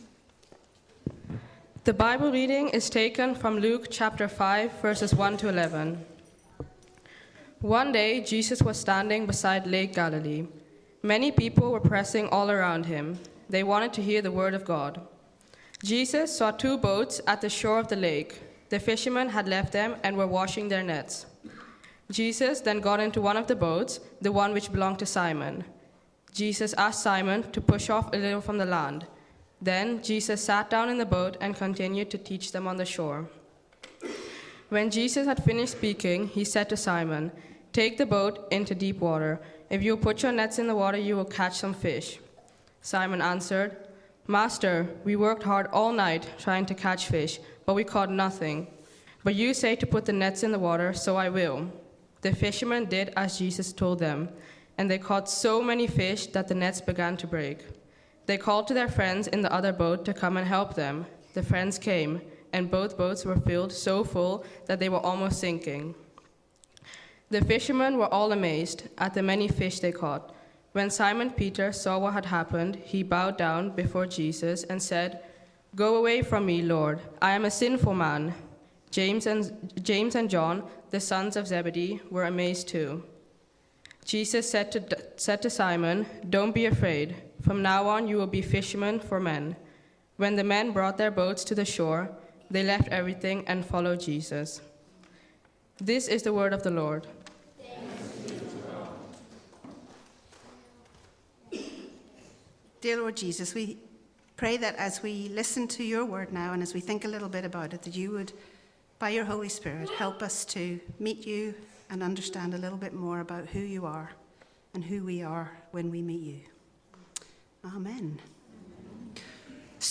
Sermon 17 August 2025 (Holiday Club wrap up service)